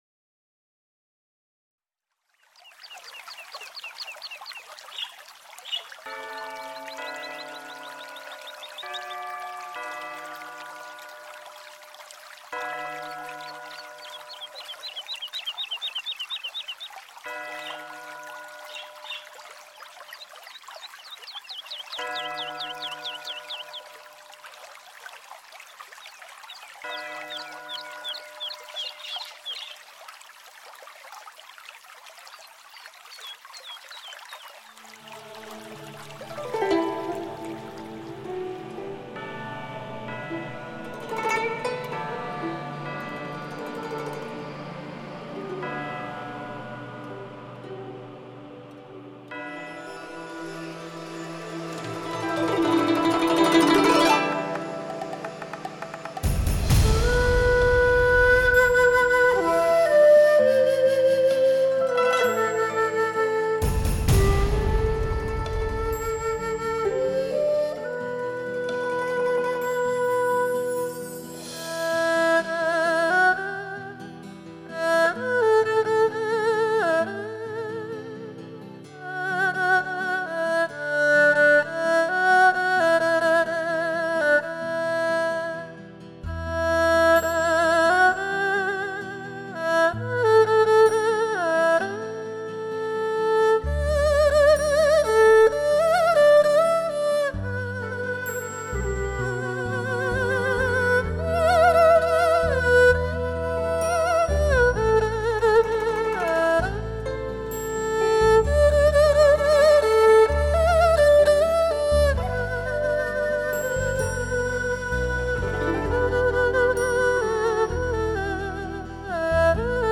一弓一弦，拙朴若诗，一诉一曲，情意真挚，
听者胸襟明净如溪流。格调别致灵动清秀，